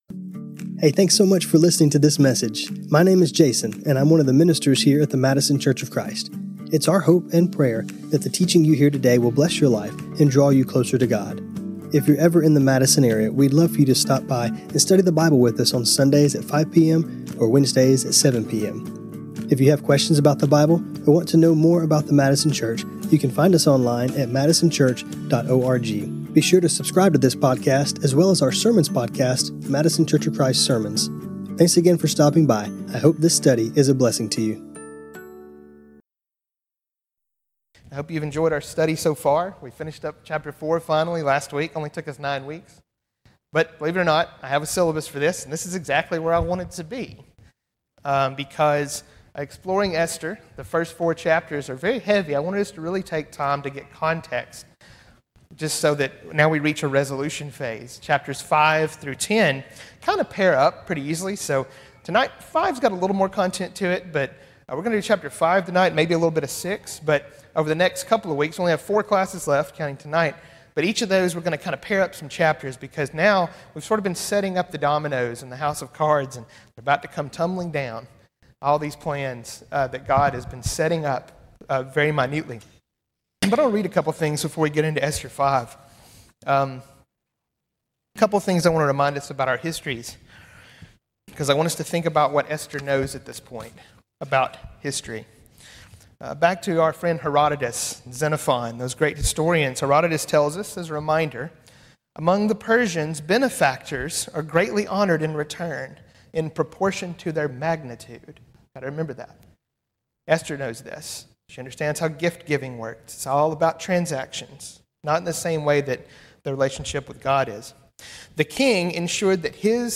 This class was recorded on Apr 8, 2026.